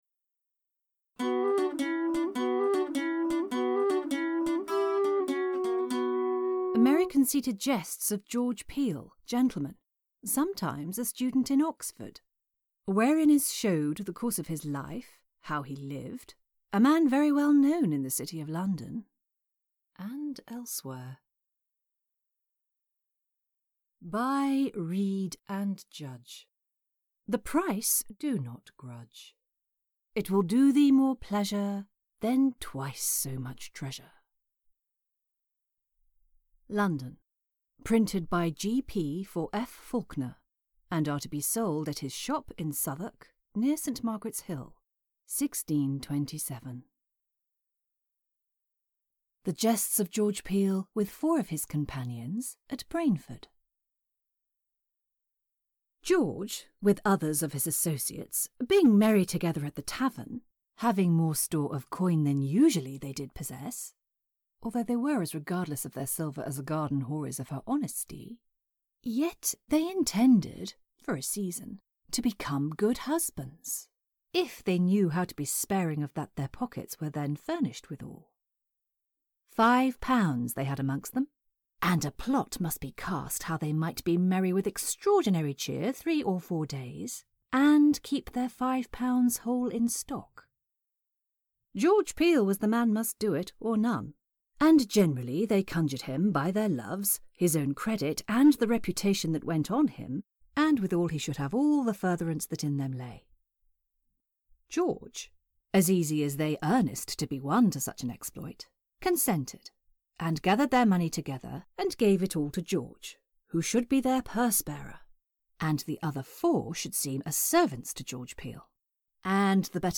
A full audiobook reading